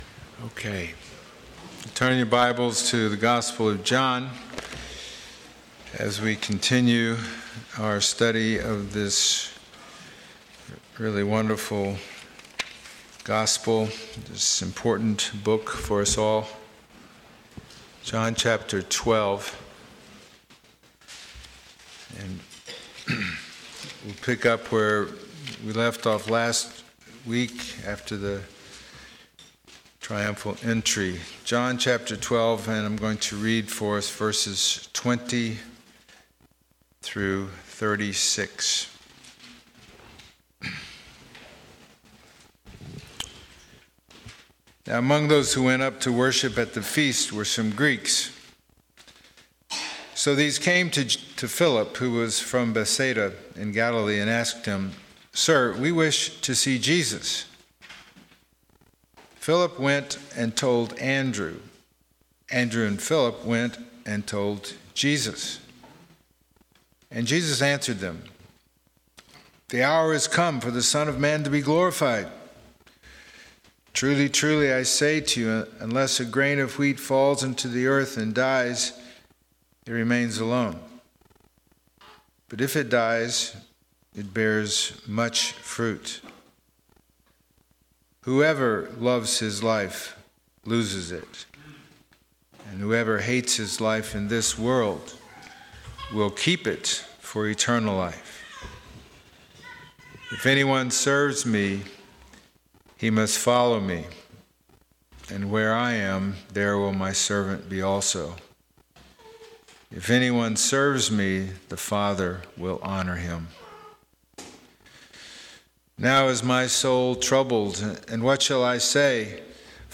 John 12:20-36 Service Type: Sunday Morning « Behold Your King!